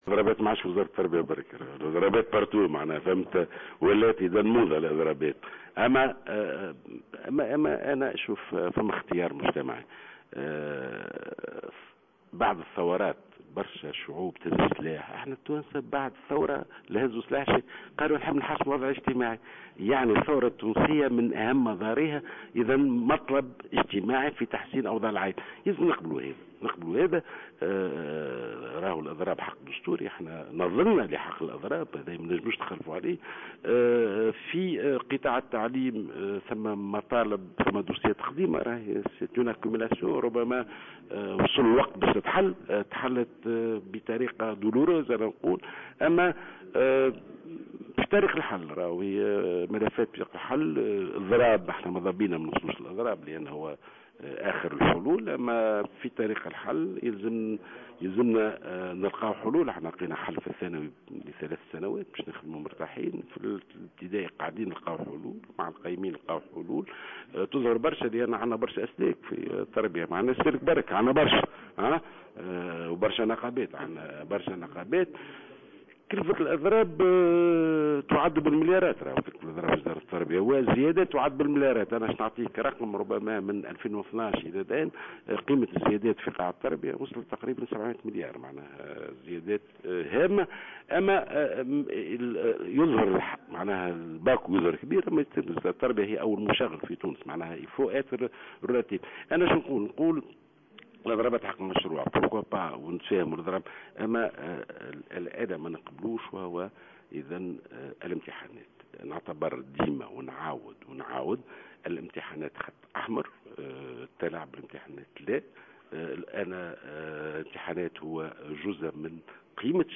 قال وزير التربية ناجي جلول في تصريح ل"جوهرة أف أم" على هامش زيارة لولاية المهدية إن قيمة الزيادات في أجور قطاع التربية بلغت 700 مليار منذ سنة 2012 إلى اليوم.